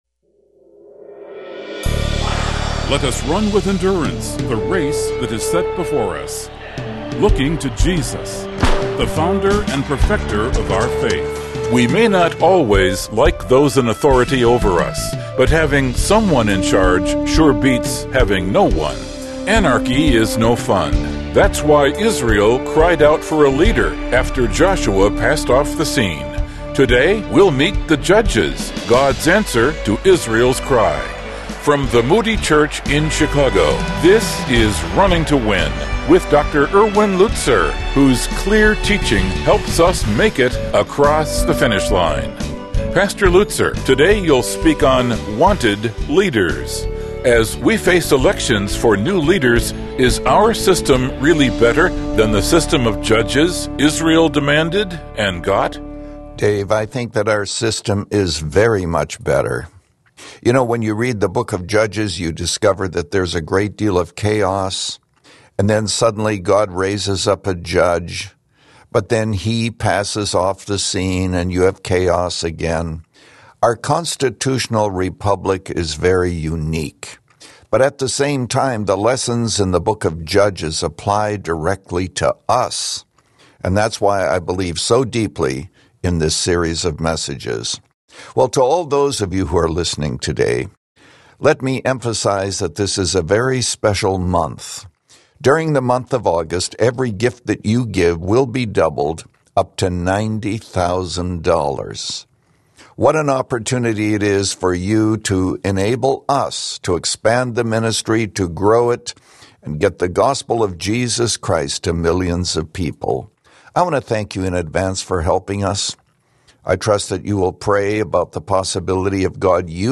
In this message from Judges 3, Pastor Lutzer identifies three stages of rebellion against God.